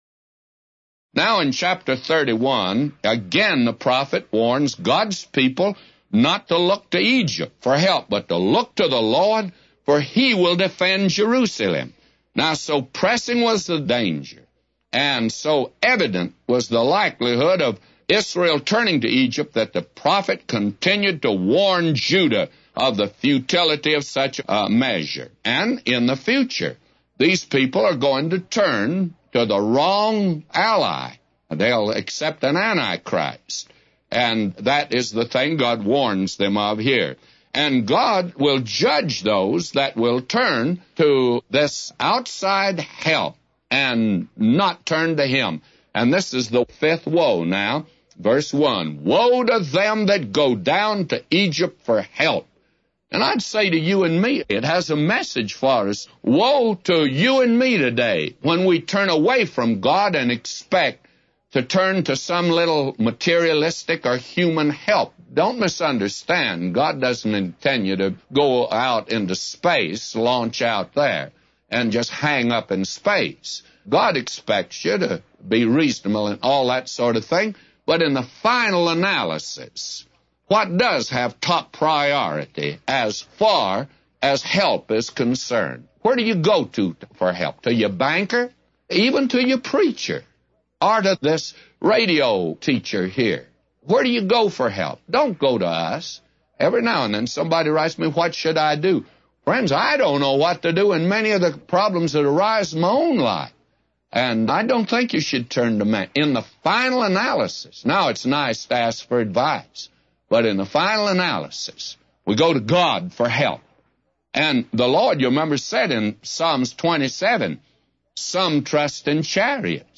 A Commentary By J Vernon MCgee For Isaiah 31:1-999